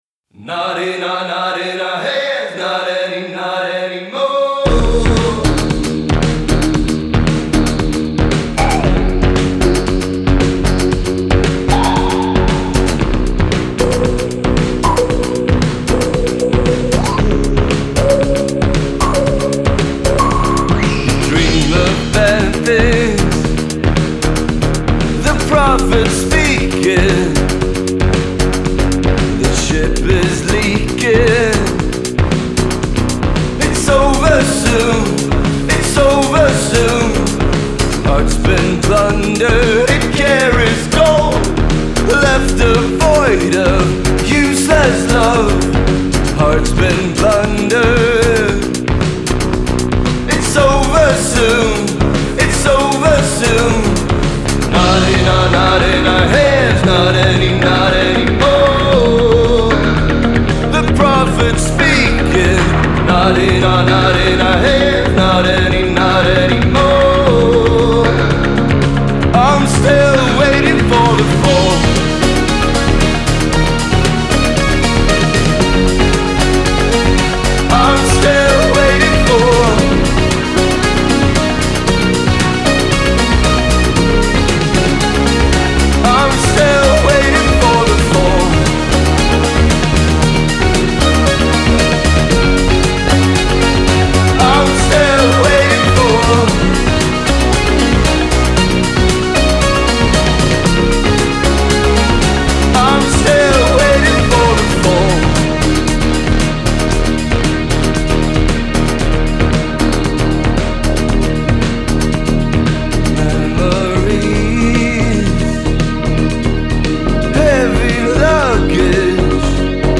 Genre: Indie/Pop/Rock